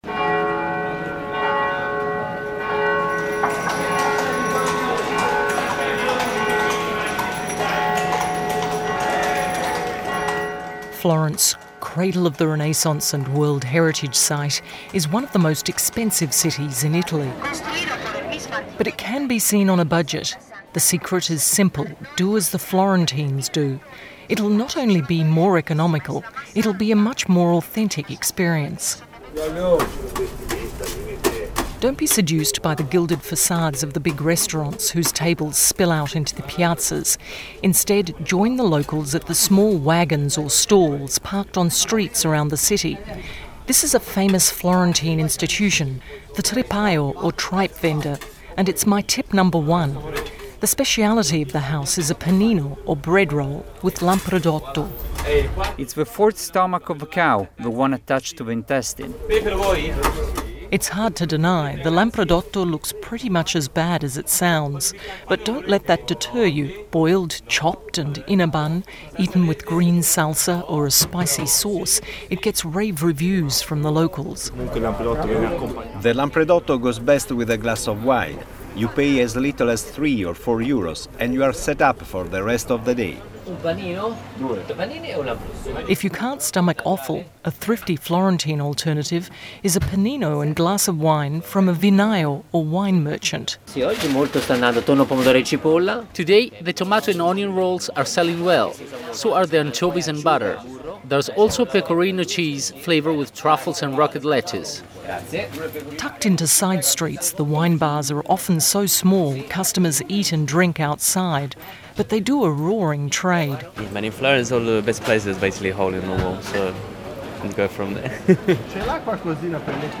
• Type Program: Radio
• Location: Florence, Italy
Deutsche Welle Radio “Pulse” feature story